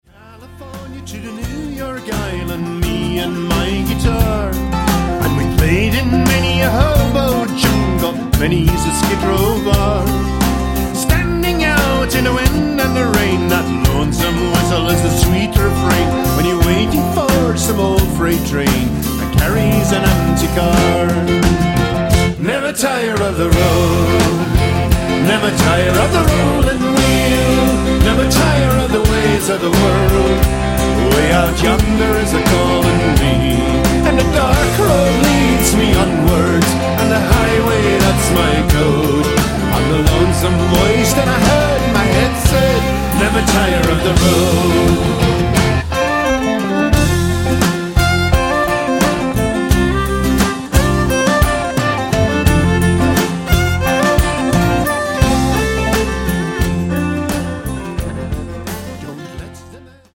the lead singer/electric mandolinist